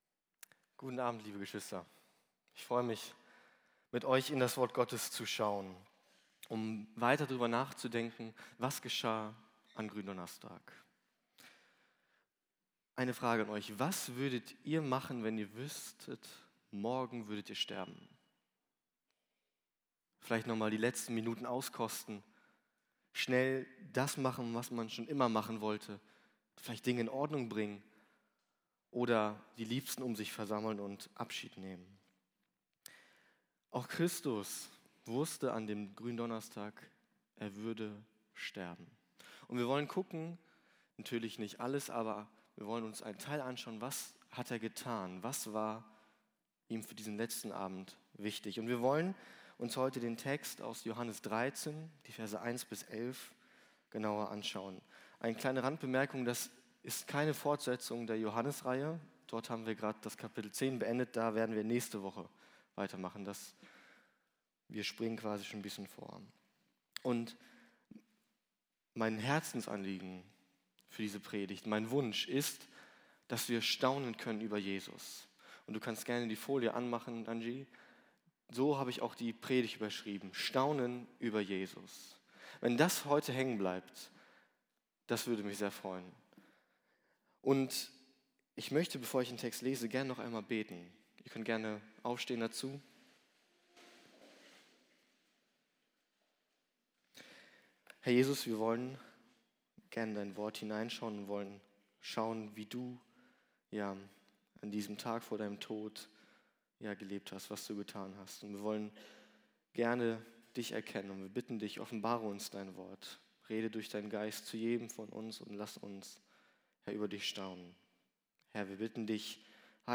Predigten der Gemeinde